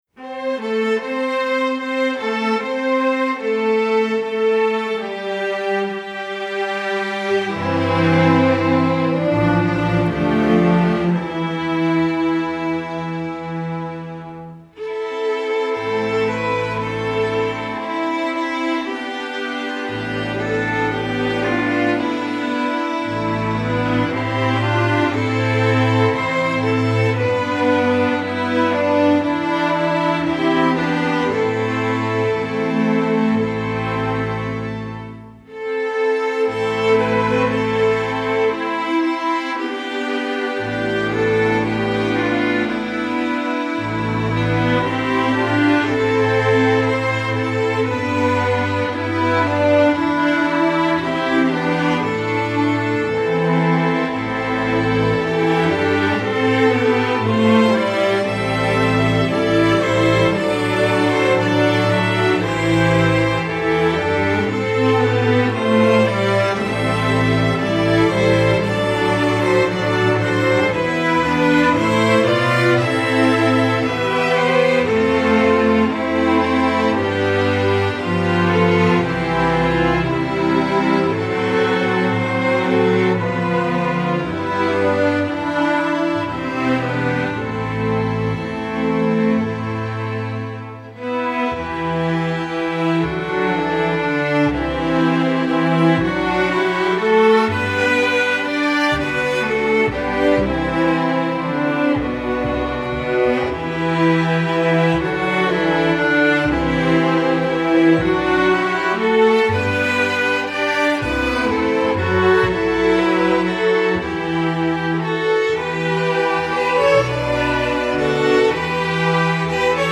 folk, sacred